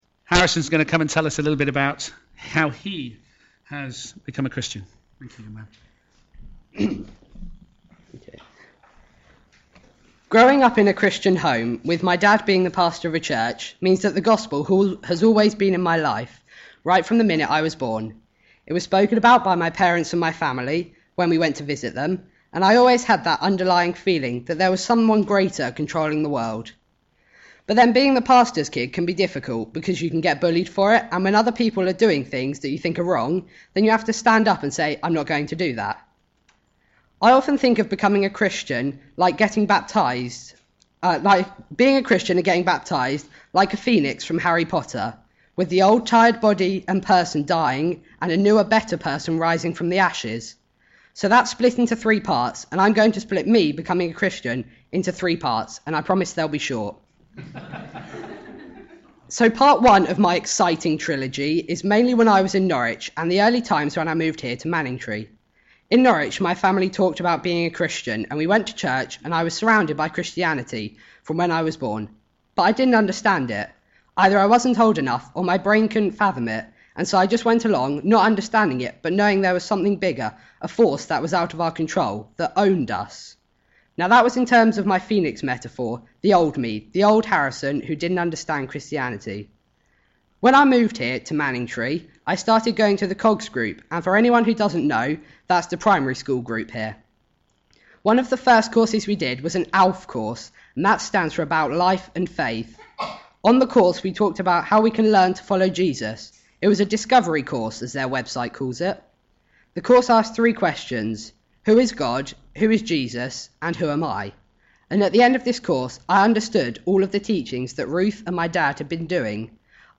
Media for a.m. Service on Sun 17th Jan 2016 10:30